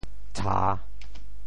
咤 部首拼音 部首 口 总笔划 9 部外笔划 6 普通话 zhà 潮州发音 潮州 ca3 文 中文解释 咤 <动> 诧异;惊奇 [be surprised] 那怪看得眼咤,小龙丢了花字,望妖精劈一刀来。